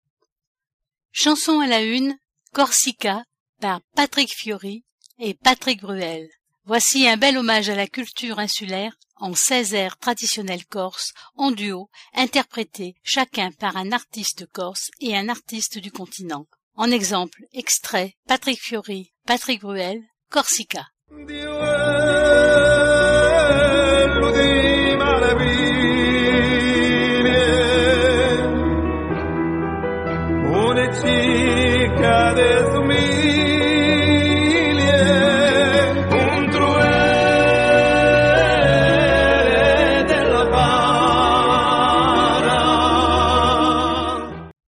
airs traditionnels corses en duos